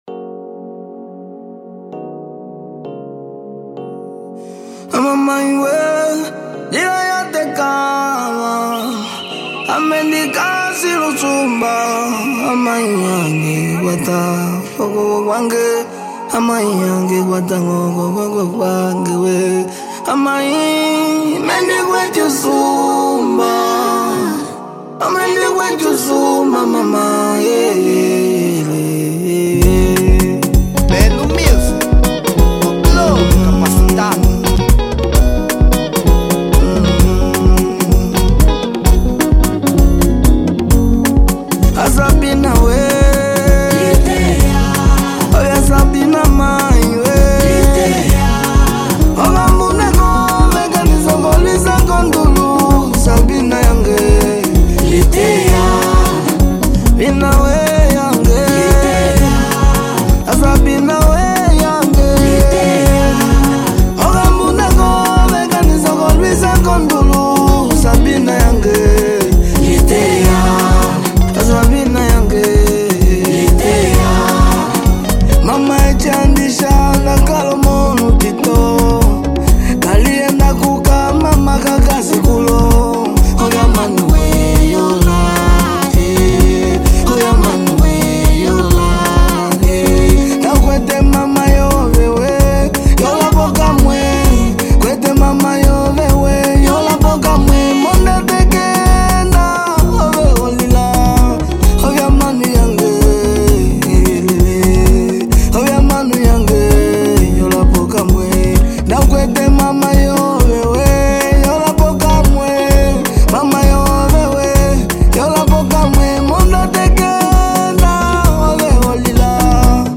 Género : Kilapanga